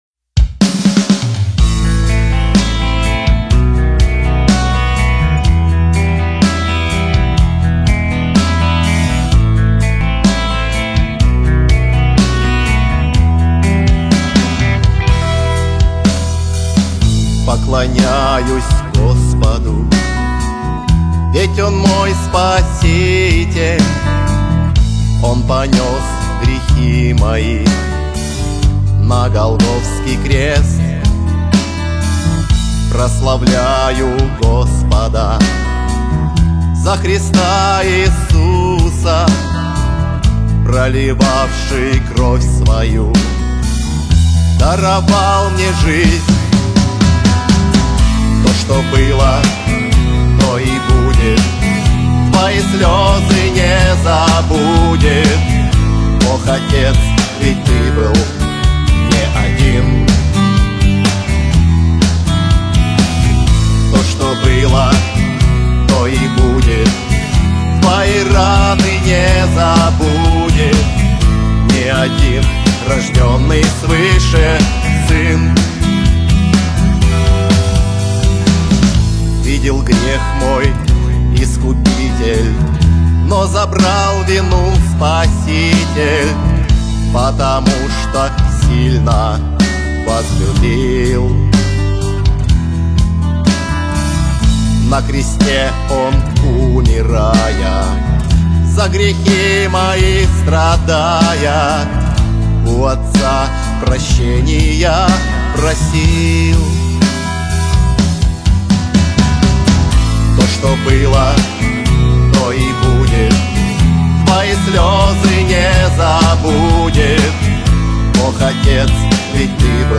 песня